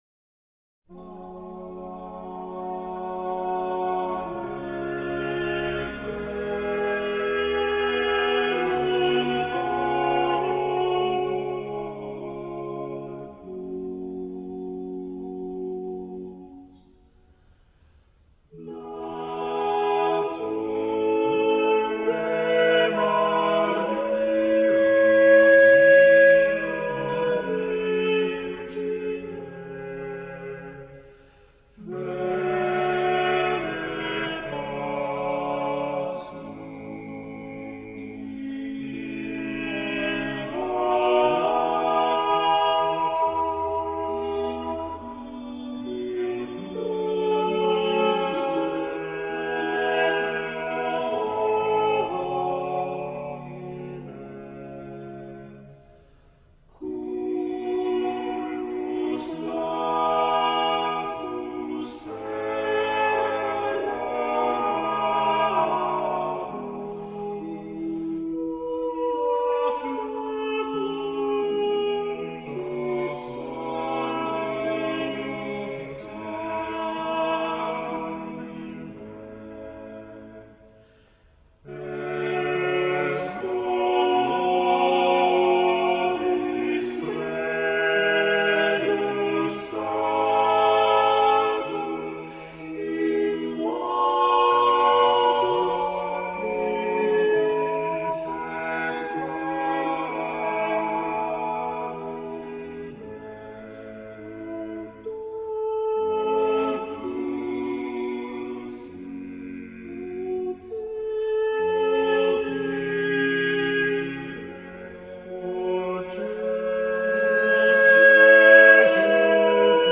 Here are some samples of great choral music, mostly own recordings made during concerts of the choirs named on this site.